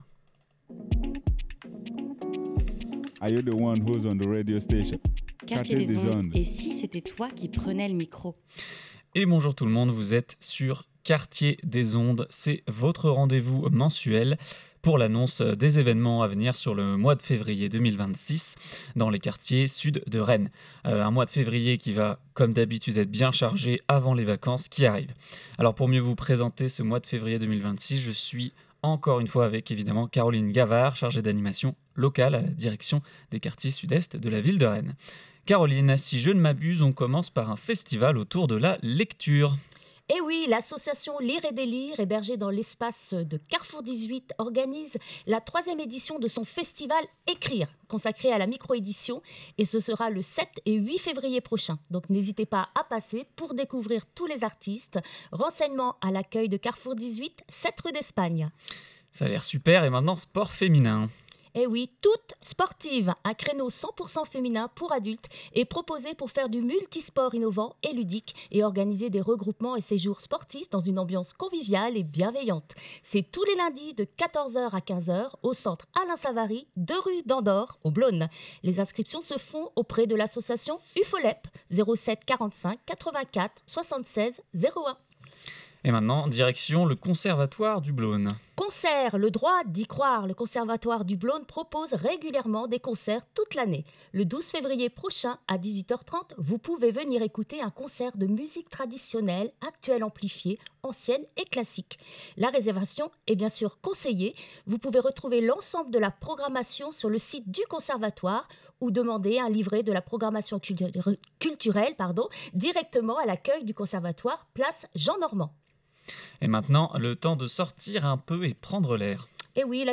Aujourd’hui, invité spécial
Une émission animée